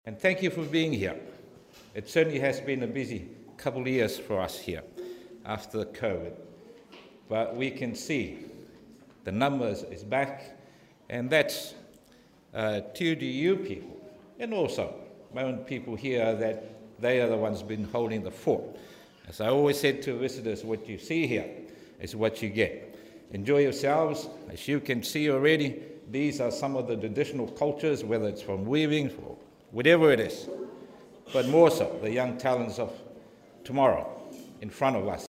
It was a full house as people turned up to the official opening of the Niue Arts Festival 2025 in their colorful attires at the old Fale Fono Foyer on Tuesday night.
In his address at the opening of the Niue Arts Festival, Prime Minister Dalton Tagelagi welcomed everyone young and old to the 3 days festival, kick starting a lineup of events for the week.